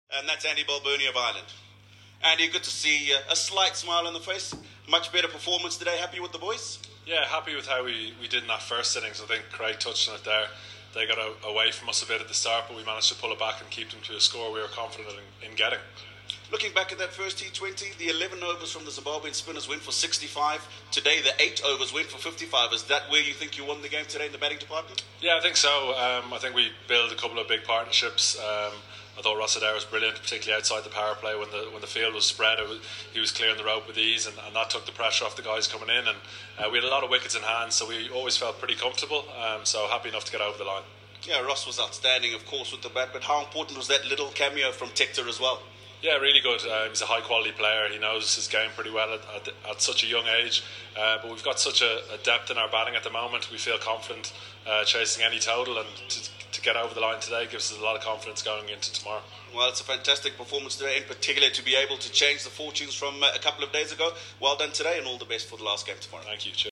Andrew Balbirnie speaks to the media after Ireland beat Zimbabwe by 6 wickets